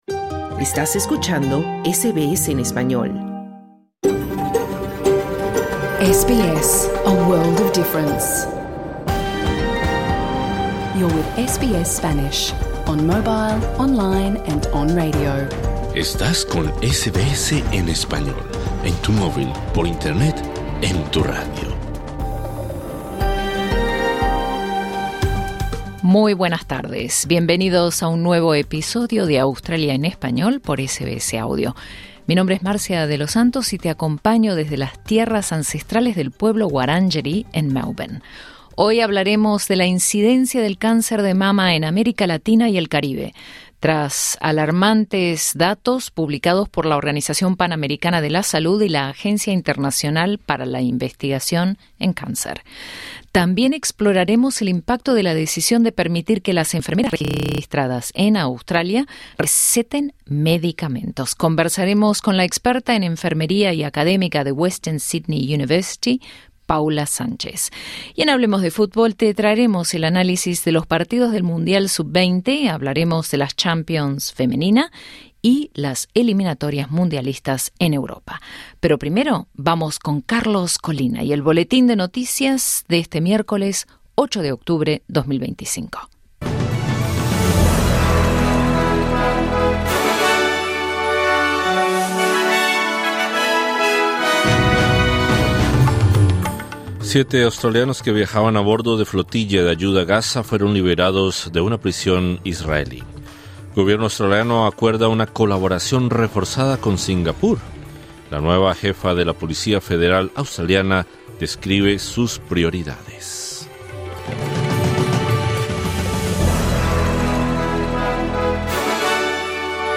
Programa en Vivo | SBS Spanish | 8 de octubre 2025 Credit: Getty Images